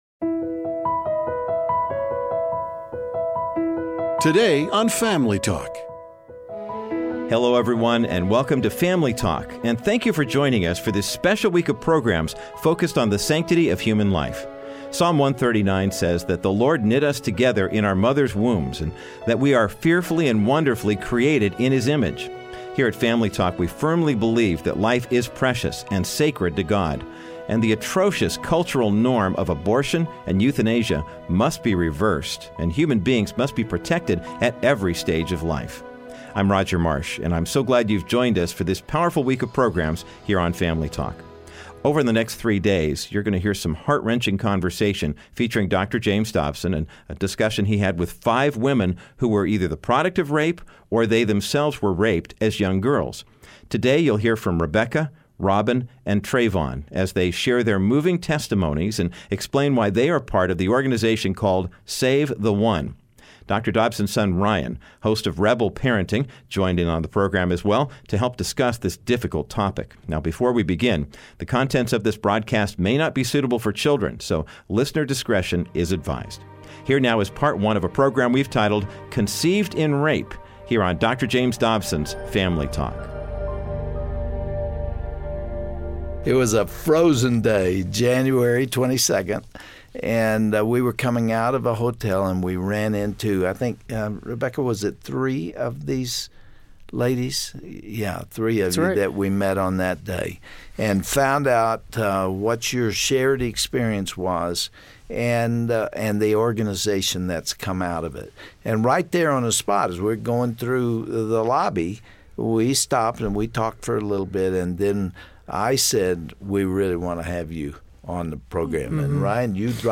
All this week on Family Talk, you will hear programs dedicated to the sacredness of human life and the reasons it should be protected. you will hear from five women who were victims of rape or are the product of their mothers being raped.